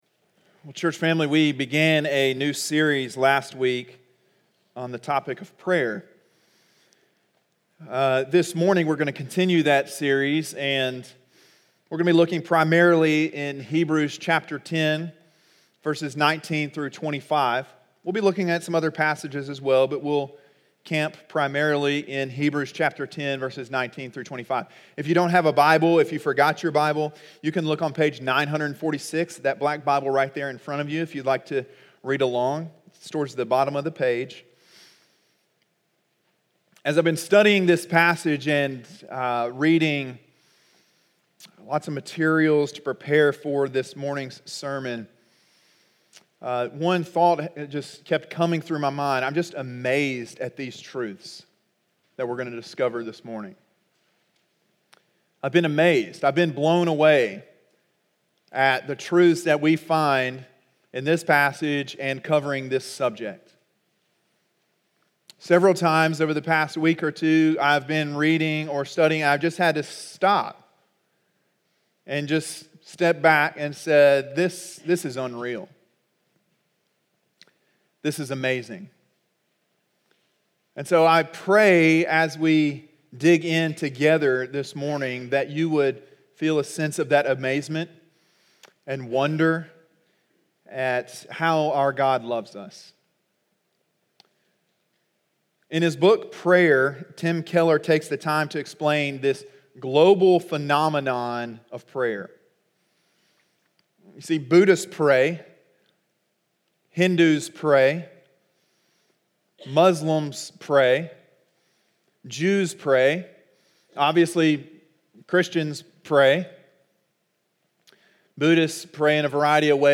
sermon1.12.20.mp3